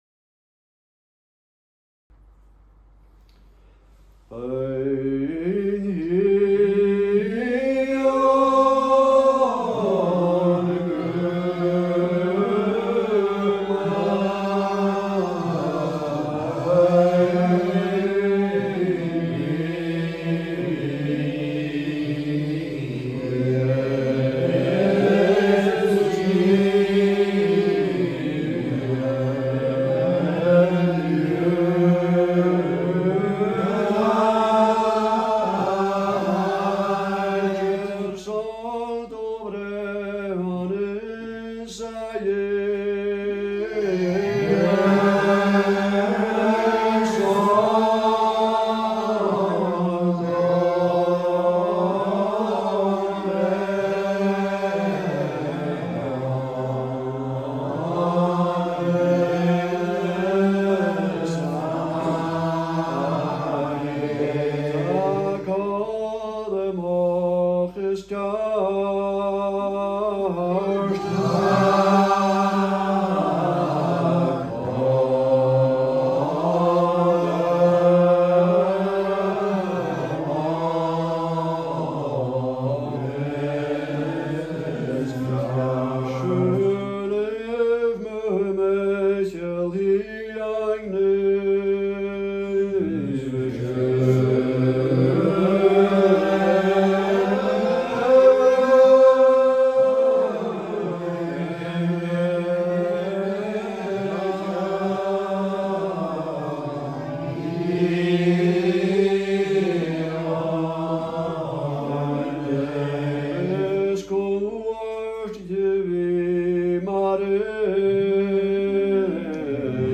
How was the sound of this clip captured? Gaelic Psalmody - class 3